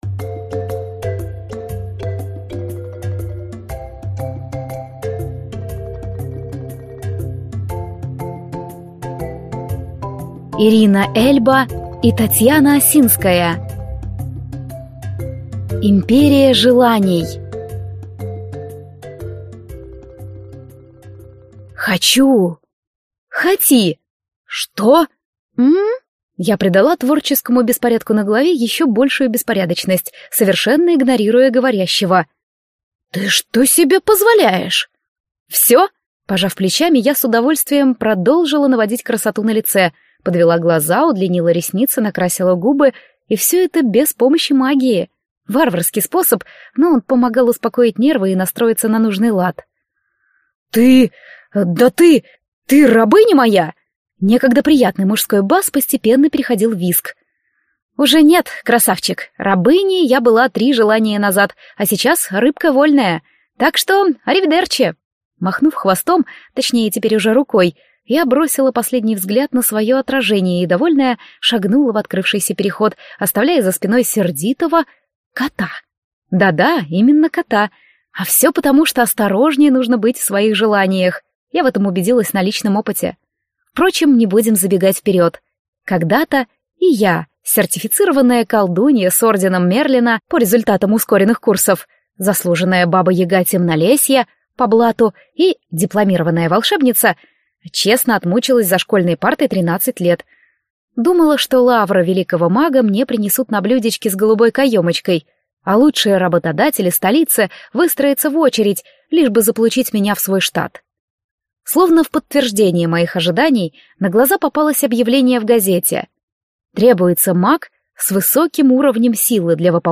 Аудиокнига Империя желаний | Библиотека аудиокниг